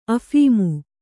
♪ aphīmu